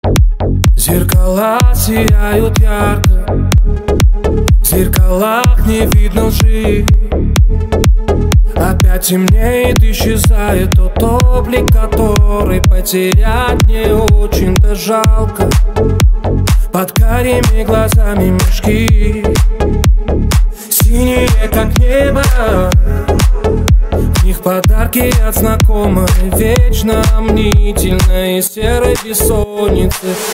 deep house
club